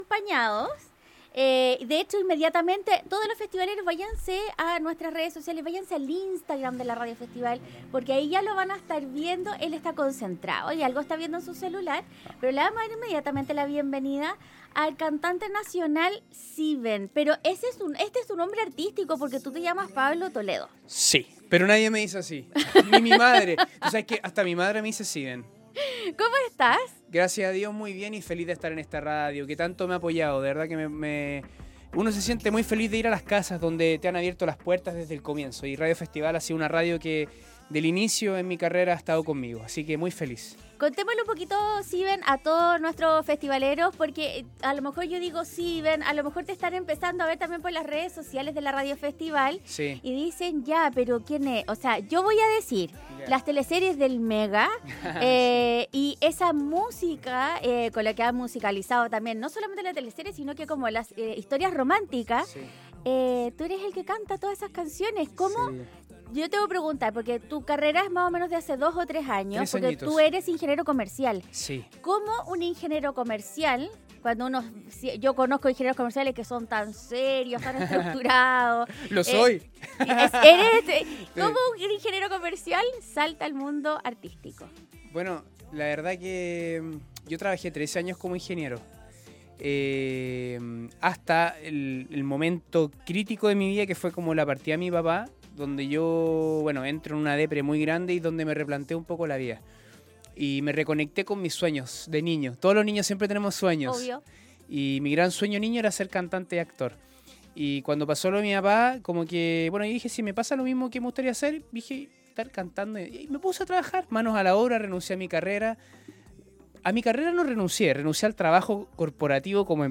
Hoy en los estudios en Colores!